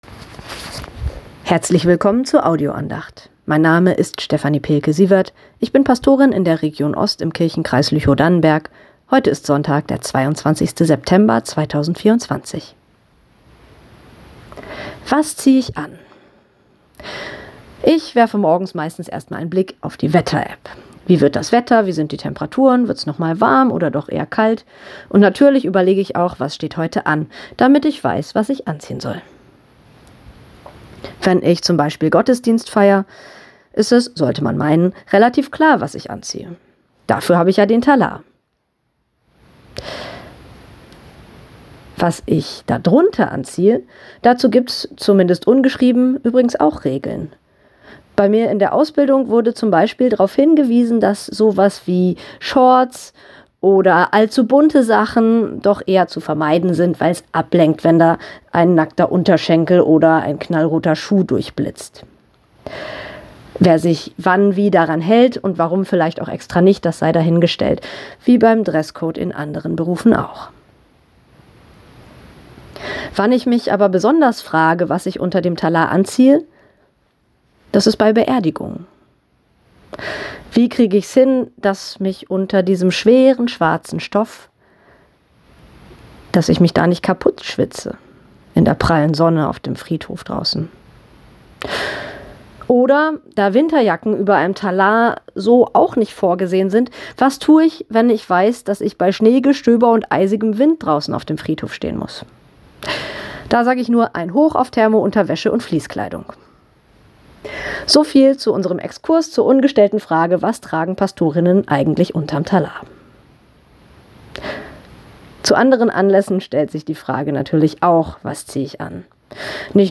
Was zieh ich an ~ Telefon-Andachten des ev.-luth.